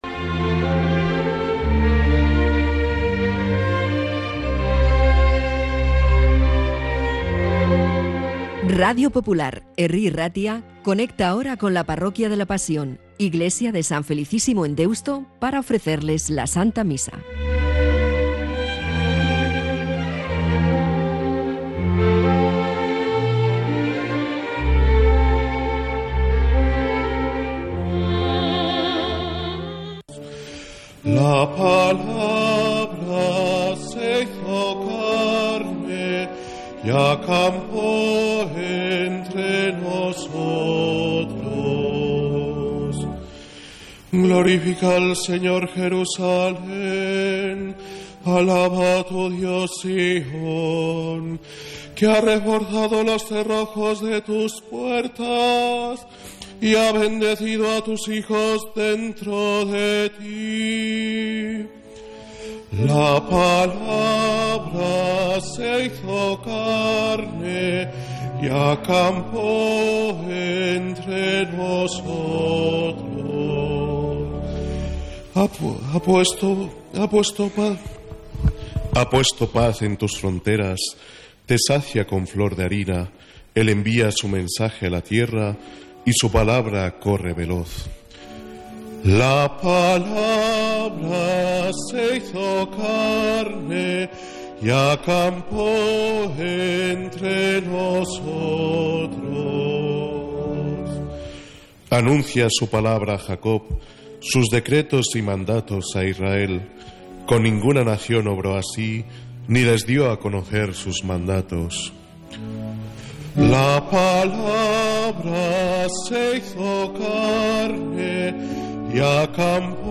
Santa Misa desde San Felicísimo en Deusto, domingo 5 de enero